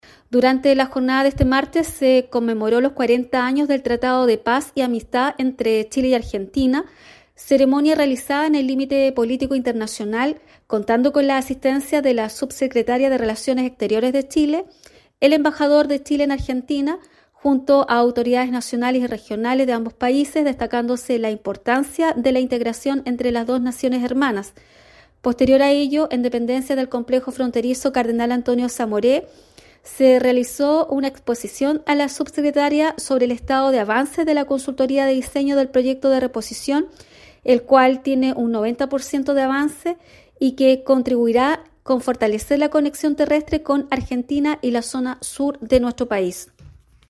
Así lo señaló la Delegada Presidencial de Osorno, Claudia Pailalef